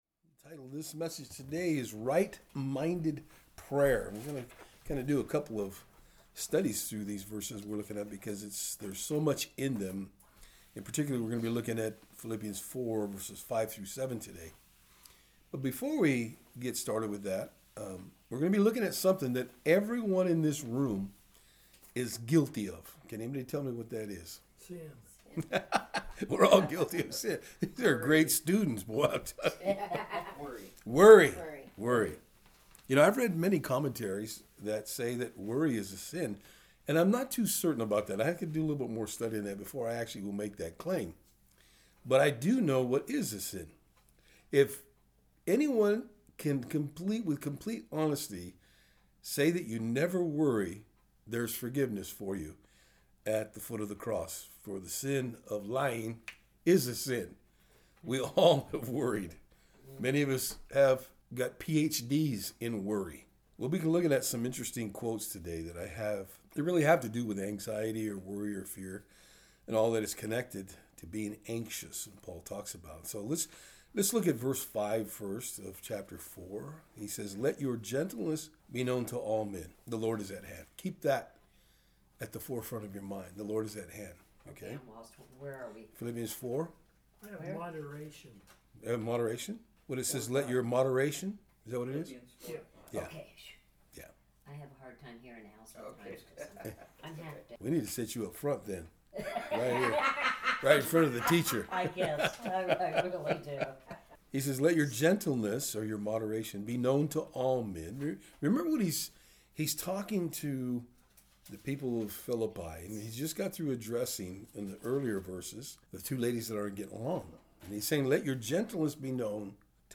Service Type: Thursday Afternoon